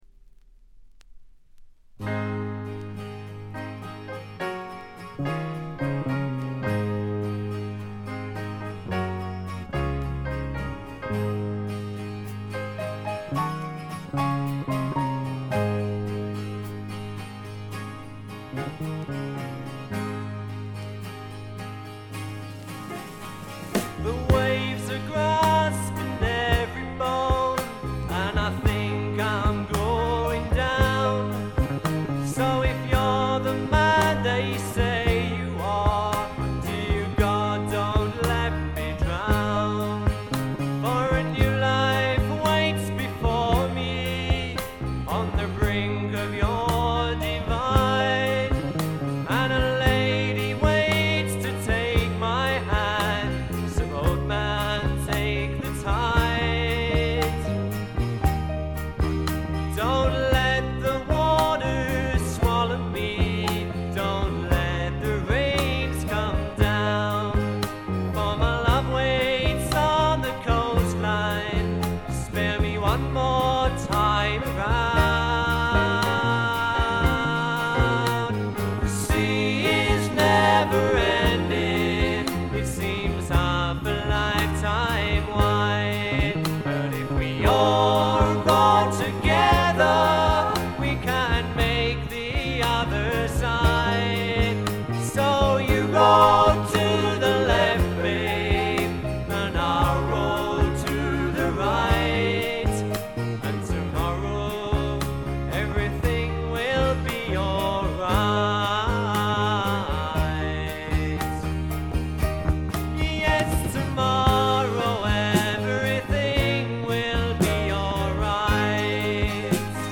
そんなわけでソロになった本作ではまさにマイルドでジェントルなフォーク／フォーク・ロック路線が満開です。
試聴曲は現品からの取り込み音源です。